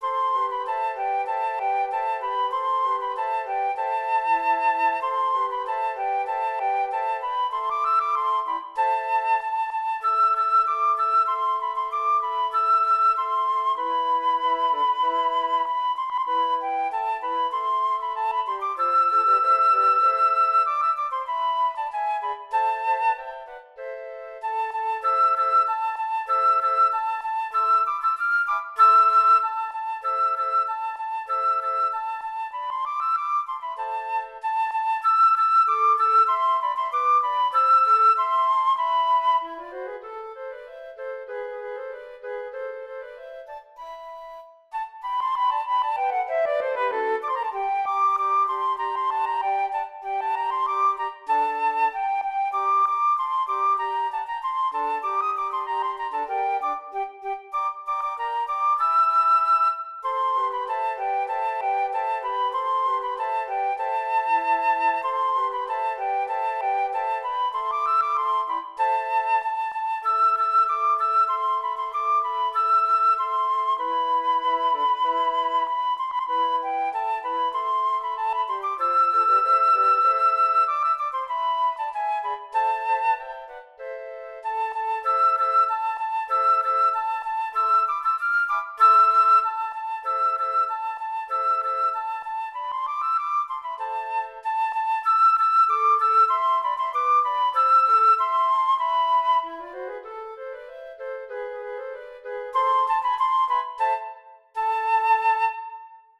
Composer: Traditional
Voicing: Flute Quartet